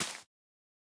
Minecraft Version Minecraft Version 1.21.5 Latest Release | Latest Snapshot 1.21.5 / assets / minecraft / sounds / block / spore_blossom / break4.ogg Compare With Compare With Latest Release | Latest Snapshot
break4.ogg